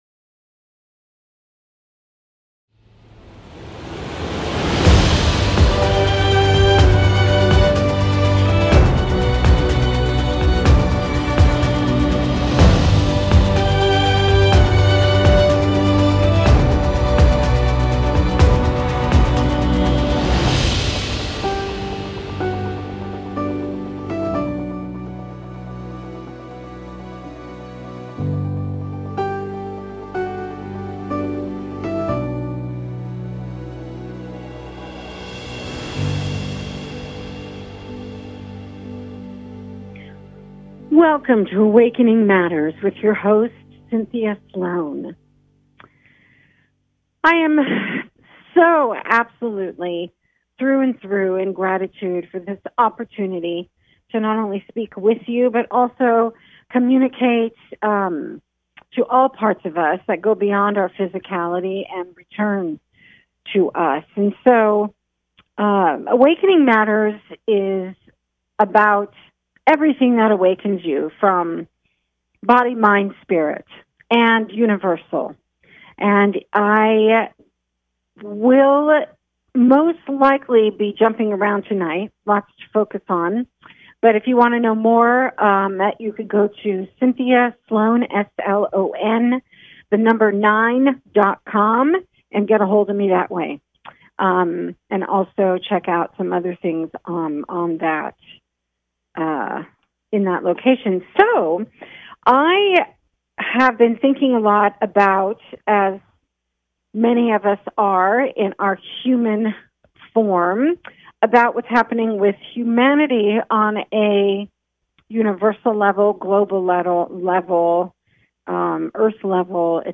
Talk Show Episode
A spiritual dialogue that invites divine wisdom, joy and laughter.